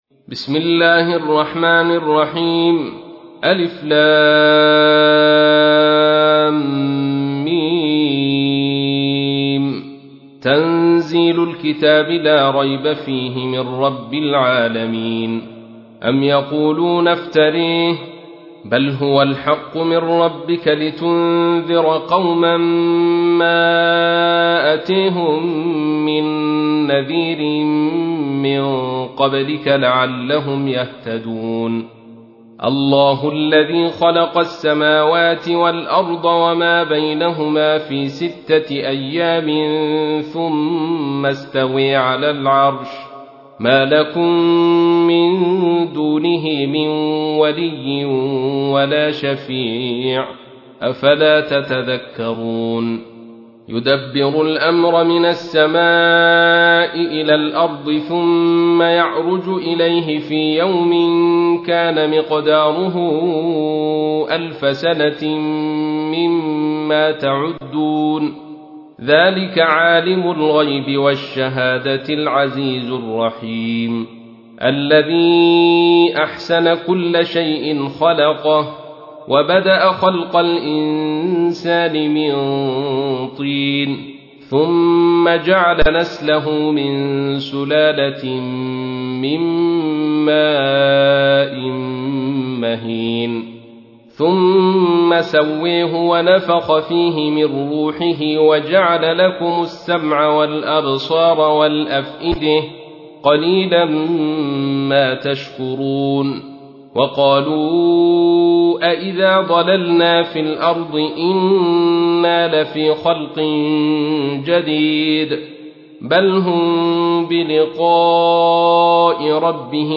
تحميل : 32. سورة السجدة / القارئ عبد الرشيد صوفي / القرآن الكريم / موقع يا حسين